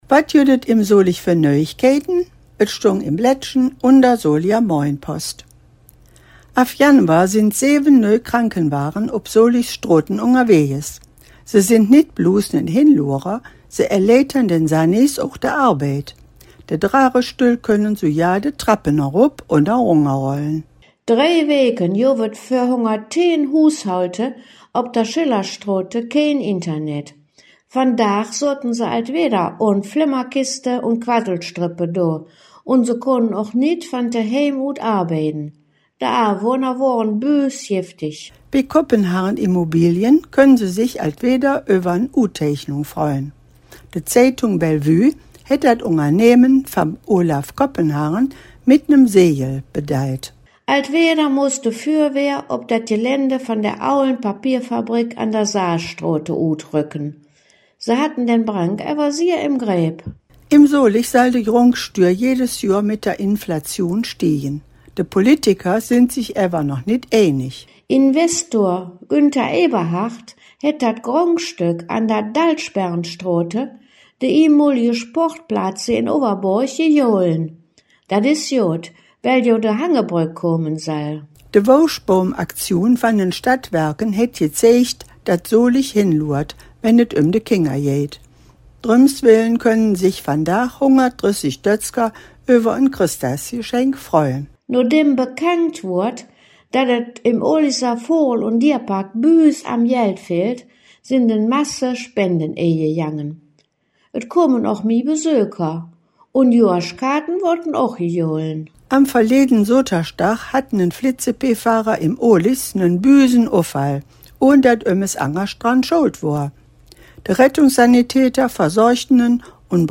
Nöüegkeïten op Soliger Platt